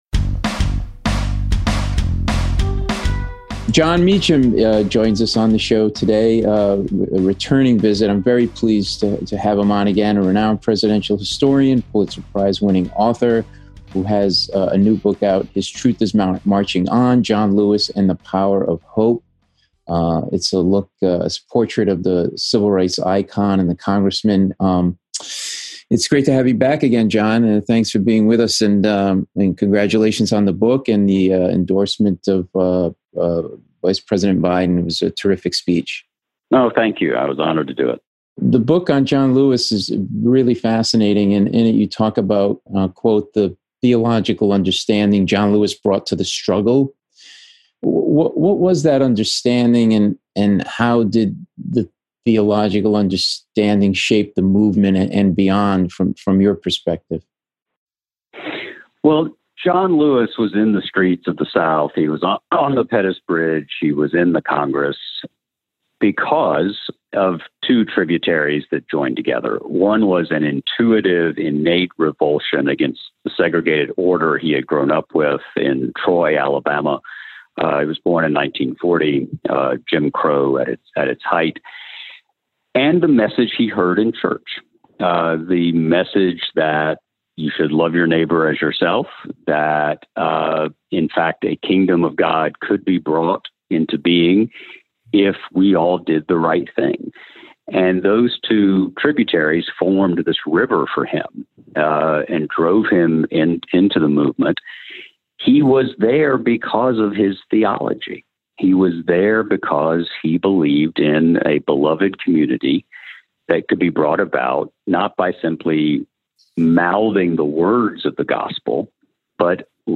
Jon Meacham - Presidential Historian, Pulitzer Prize-Winning Author (Paul Mecurio interviews Jon Meacham; 25 Aug 2020) | Padverb